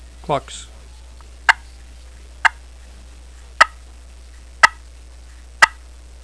Listen to  6 seconds of clucks
• Makes excellent raspy and smooth yelps, clucks, purrs, whines, and cutts at any volume
ccscratchclucks6.wav